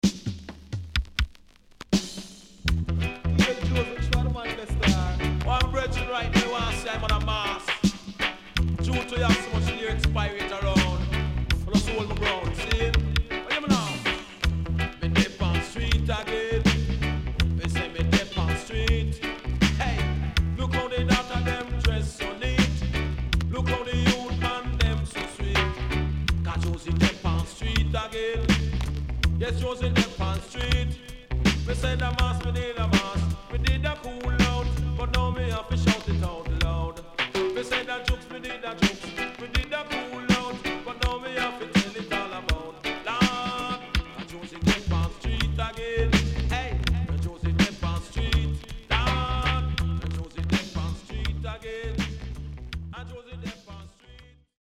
SIDE A:プレス起因により所々ノイズ入ります。